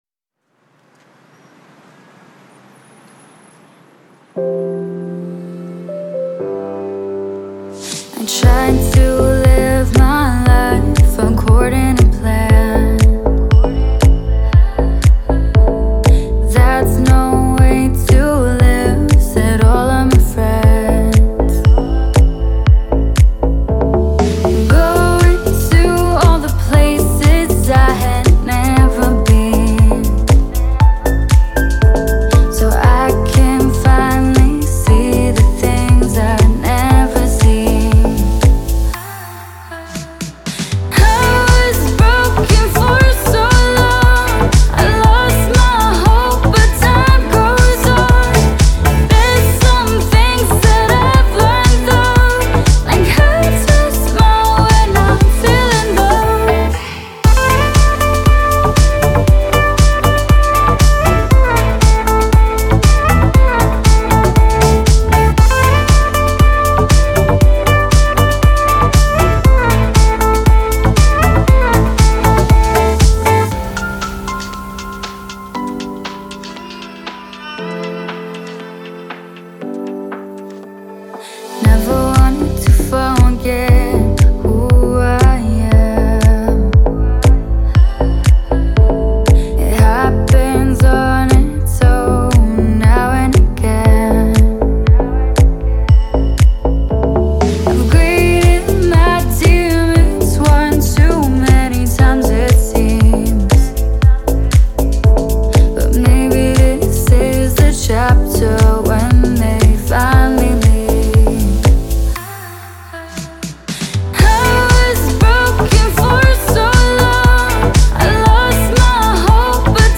это трек в жанре электронной музыки с элементами попа